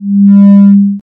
error_alarm.ogg